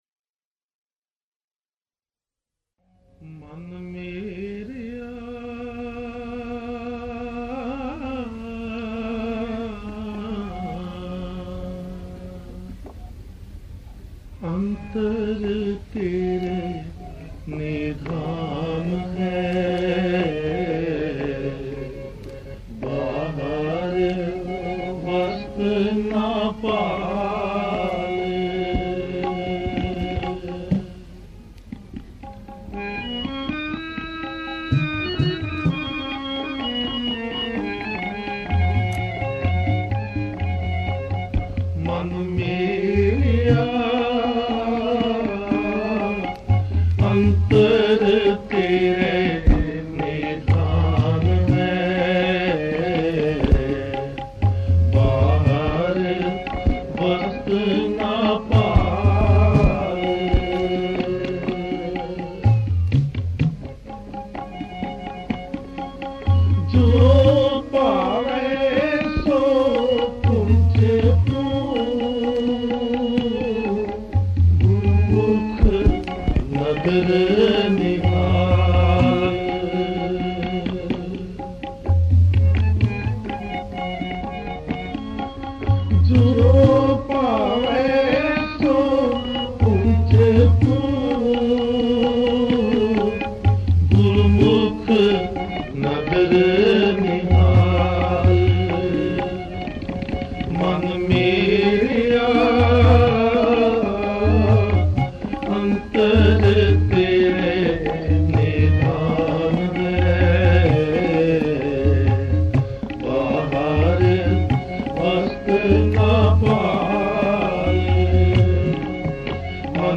This shabad is by Guru Amar Das in Raag Vadhans on Page 487 in Section ‘Sun Baavare Thoo Kaa-ee Dekh Bhulaana’ of Amrit Keertan Gutka.